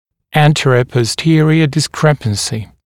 [ˌæntərəpɔs’tɪərɪə dɪs’krepənsɪ][ˌэнтэрэпос’тиэриэ дис’крэпэнси]нарушение в соотношении верхней и нижней челюстей в сагиттальной плоскости, сагиттальное несоответствие